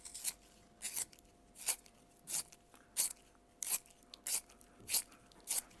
Sacapuntas
Sonidos: Oficina